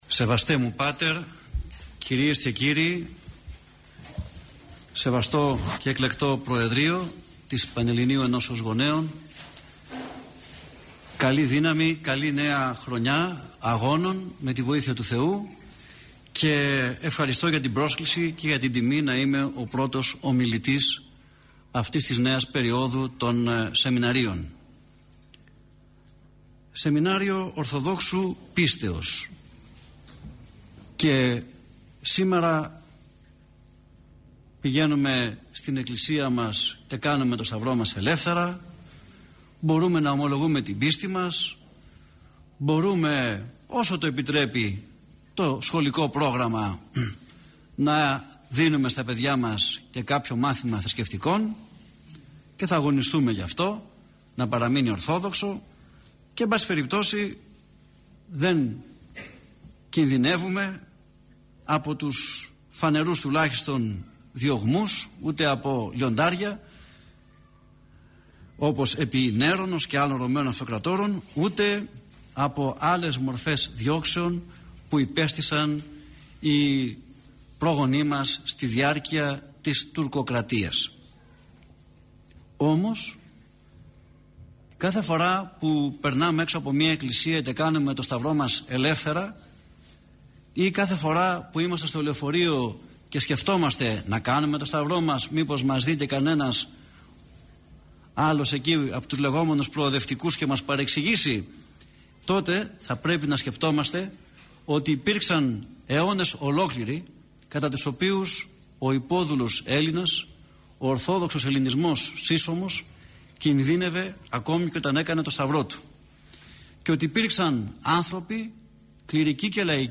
Η ομιλία αυτή “δόθηκε” τις 18 Οκτωβρίου του 2009, στα πλαίσια του σεμιναρίου Ορθοδόξου πίστεως – του σεμιναρίου οικοδομής στην Ορθοδοξία. Το σεμινάριο αυτό διοργανώνεται στο πνευματικό κέντρο του Ιερού Ναού της Αγ. Παρασκευής (οδός Αποστόλου Παύλου 10), του ομωνύμου Δήμου της Αττικής.